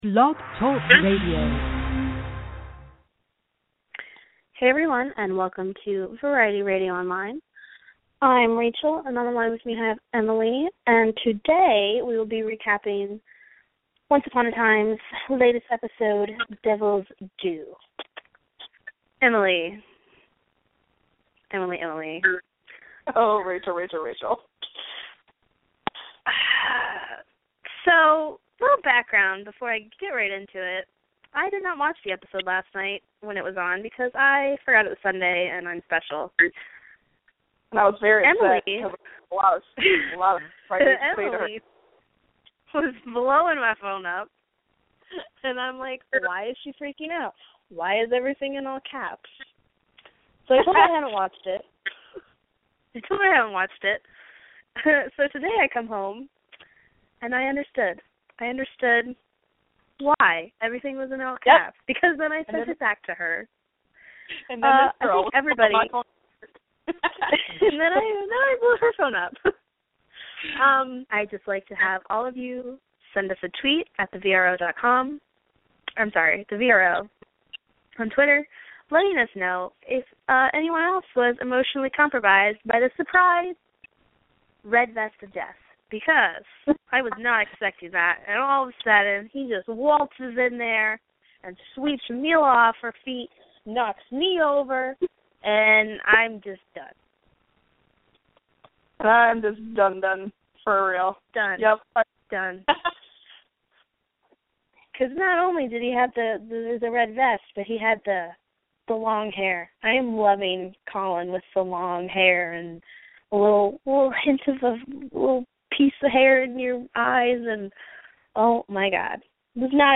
Join our Once Upon a Time hosts every Monday as they discuss the highlights of the latest episode of Once Upon a Time - E-mail your thoughts on the episode and the host will read them on air.